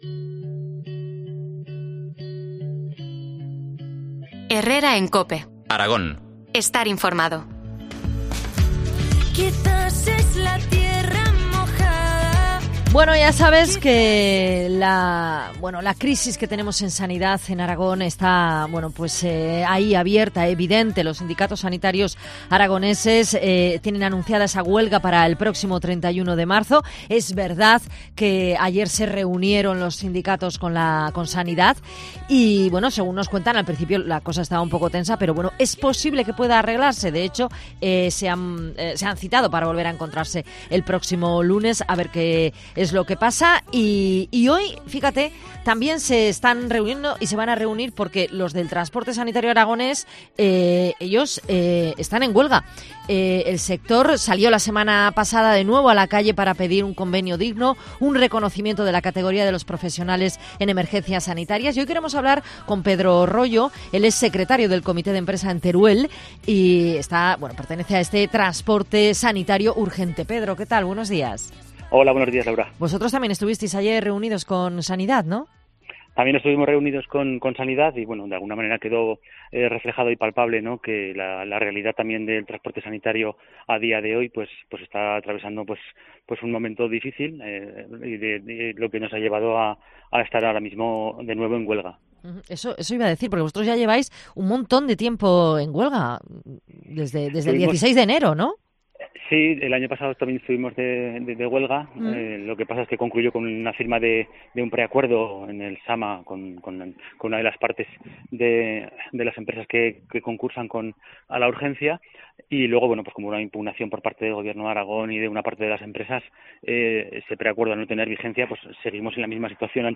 Hablamos